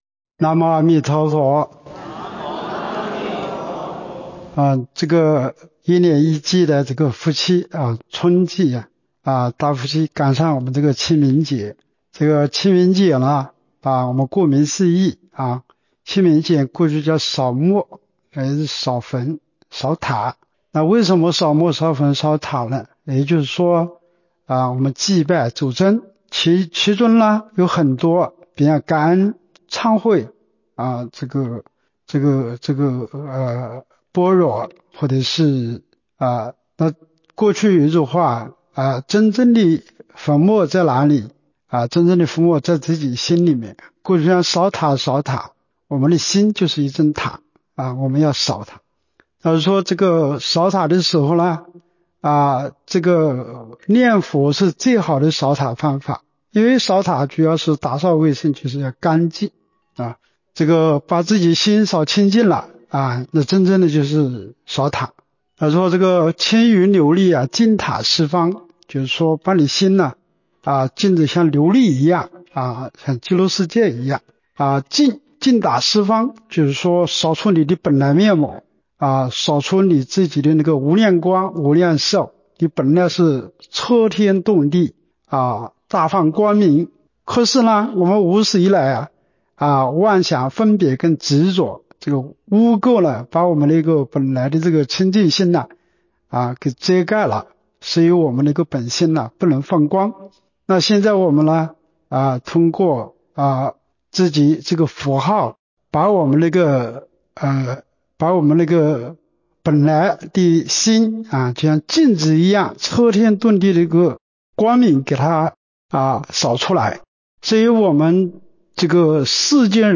德安清净庵洒净开示